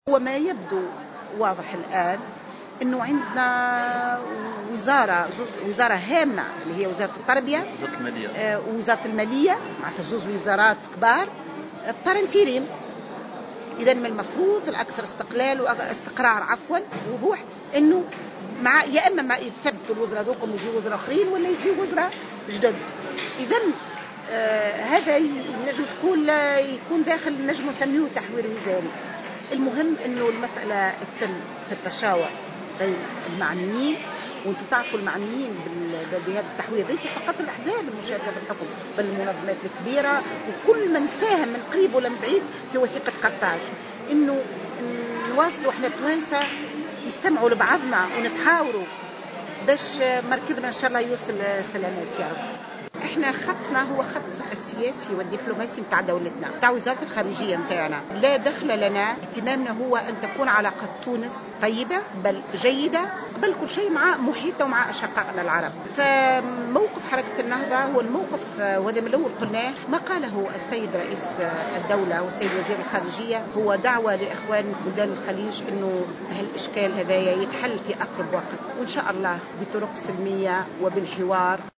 وأضافت العبيدي في تصريح لمراسل الجوهرة اف ام على هامش ندوة وطنية انتظمت اليوم بالمسنتير حول أسس أهداف التنمية المستدامة بتونس من تنظيم أعضاء مجلس نواب الشعب، أن التحوير الوزاري، يجب أن يتم بعد التشاور مع جميع الأحزاب والمنظمات الموقعة على وثيقة قرطاج.